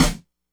snare01.wav